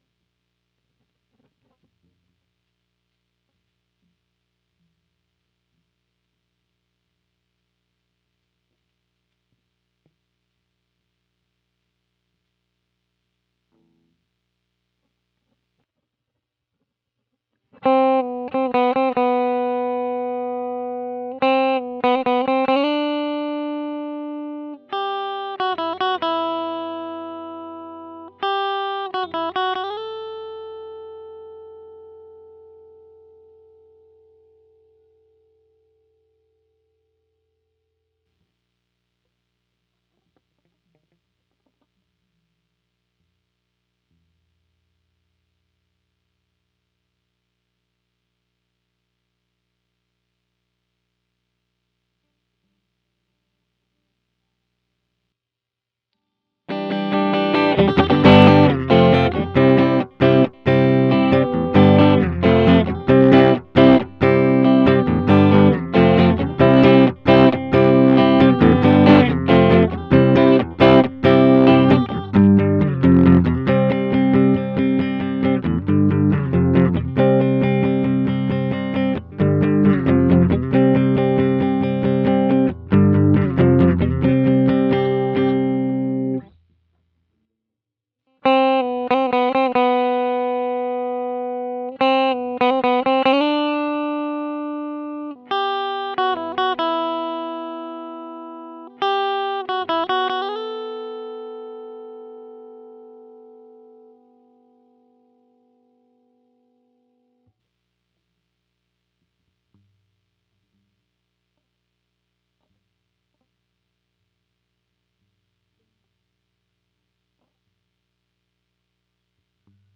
Guitar_013.wav